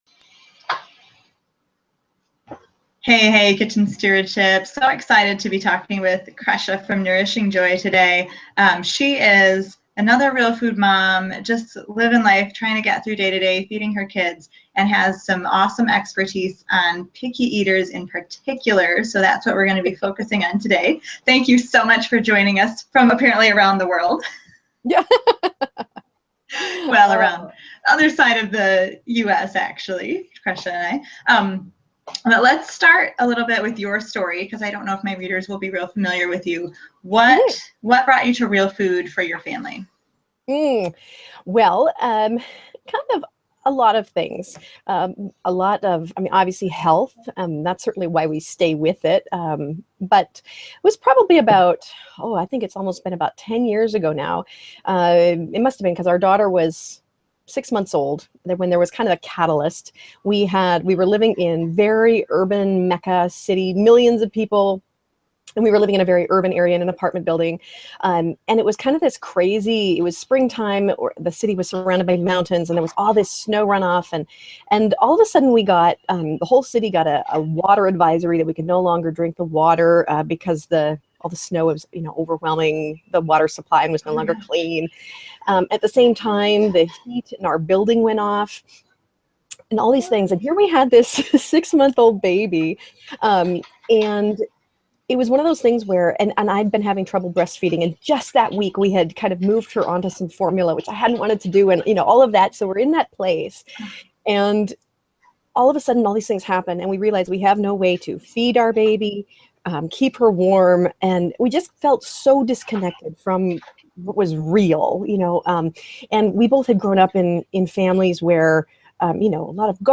Break out of bad habits with surprising new situations and expand your picky eater's palate - excellent interview!